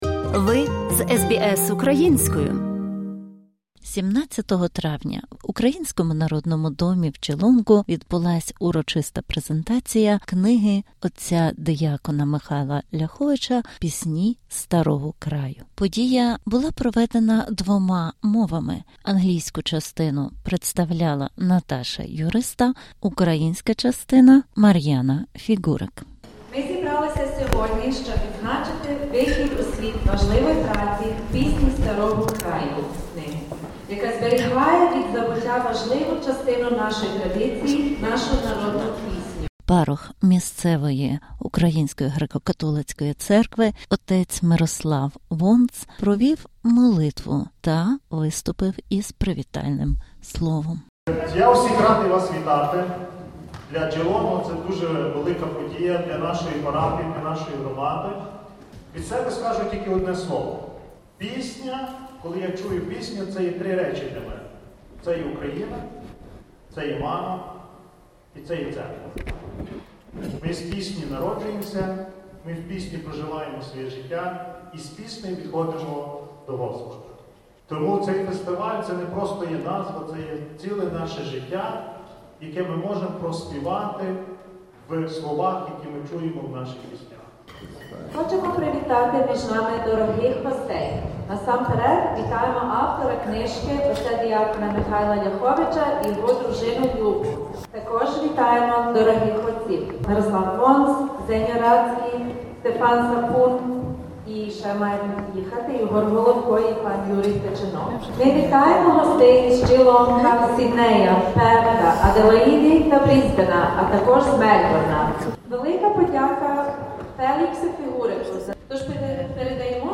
Неперевершена пісенна спадщина України: репортаж із презентації збірки «Пісні старого краю»
Репортаж підсумовує презентацію книги «Пісні Старого Краю» – культурного заходу, присвяченого неперевершеній музичній спадщині України.
17 травня 2025 року в Українському домі, що у Джілонґу, штат Вікторія українська громадськість дружньо зібралась на презентацію книги «Пісні старого краю».